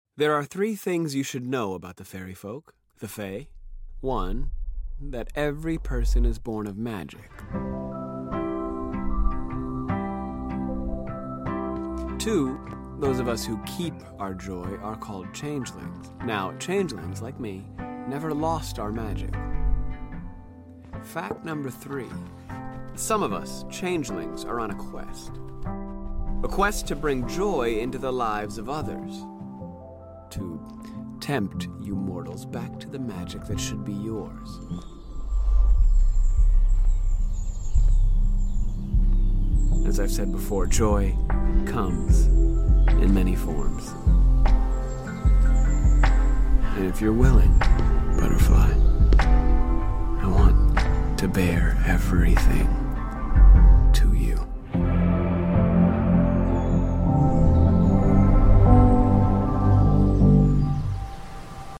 Please enjoy the official trailer for The Misty Door, voiced by Jesse Williams.